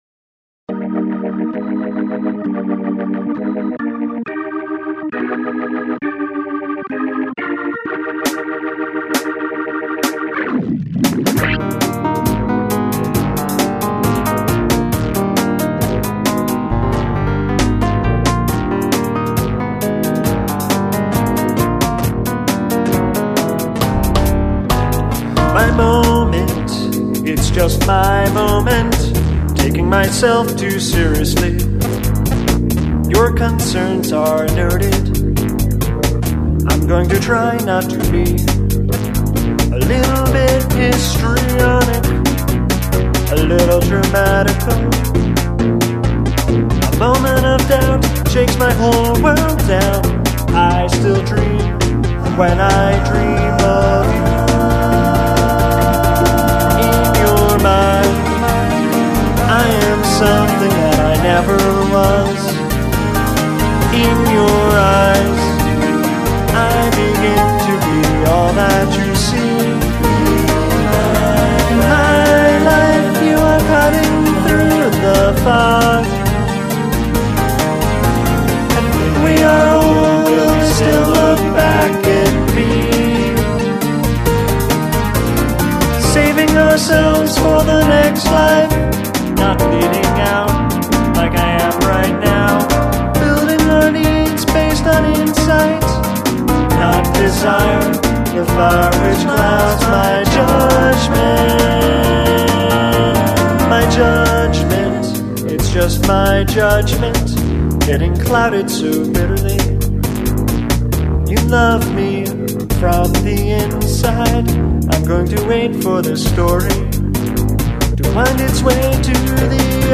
Fun and tightly produced and appropriately self-deprecating.